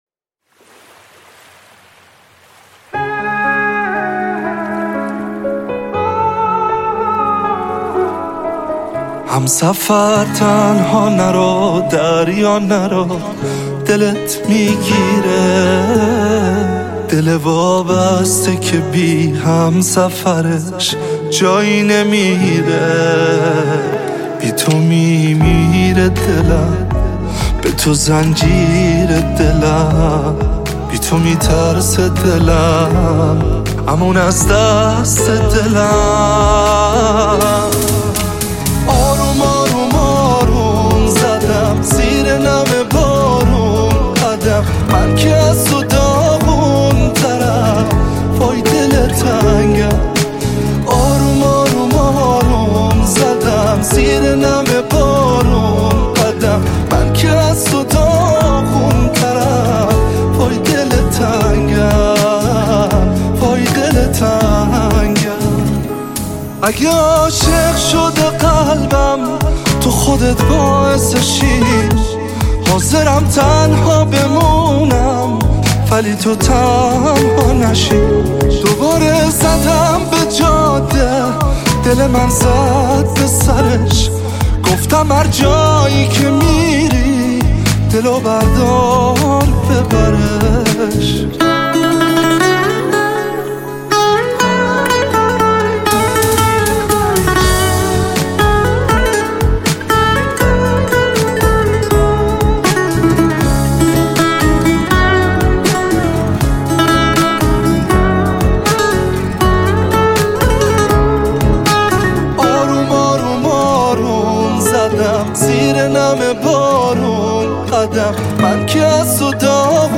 صداش خیلی خیلی زیباست و با احساس میخونه